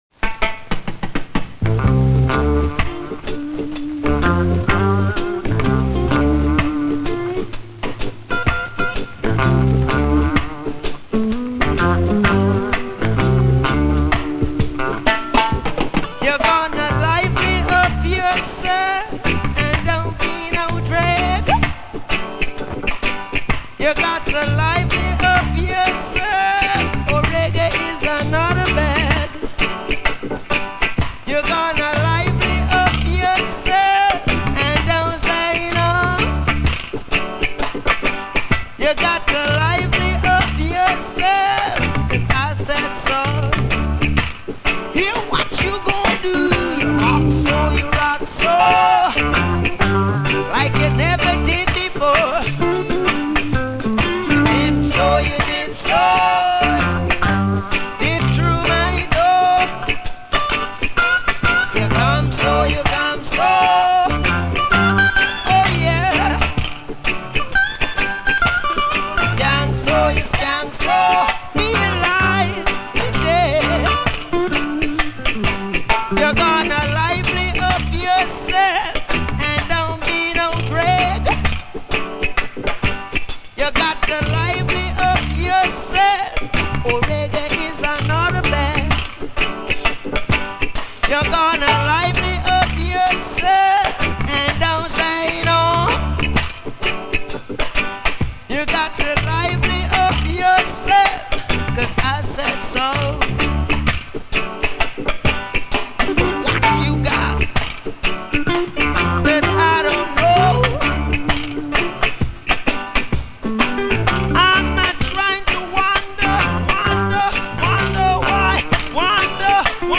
Reggae, Roots Reggae